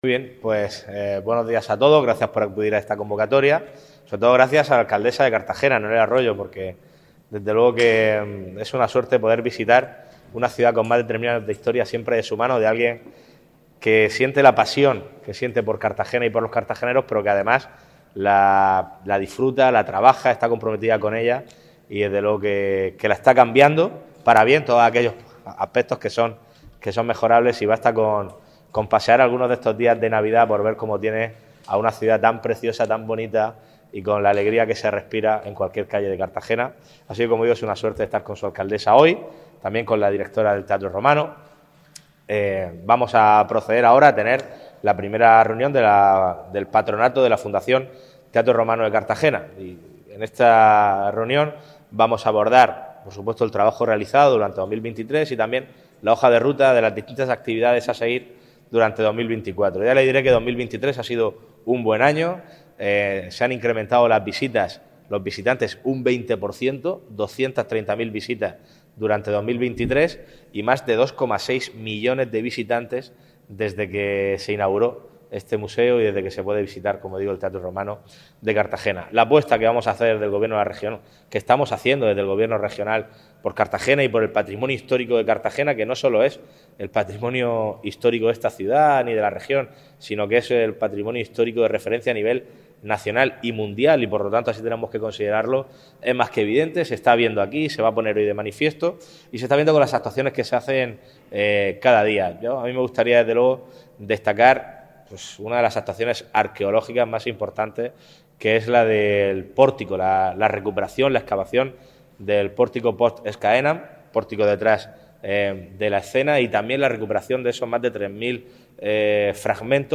Enlace a Declaraciones de Fernando López Miras y Noelia Arroyo